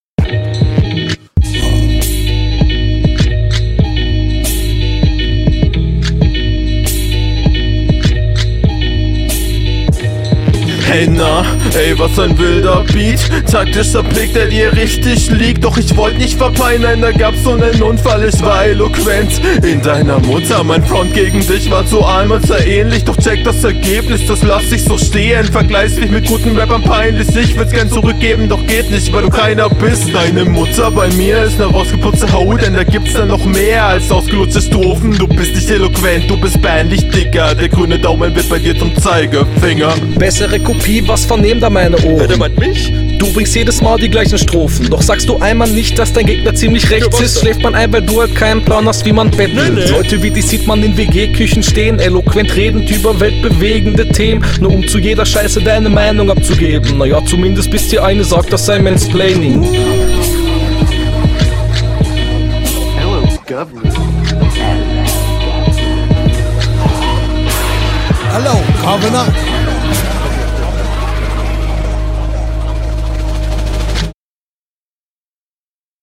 Flow schon cool, aber in der Mitte n bissl vernuschelt.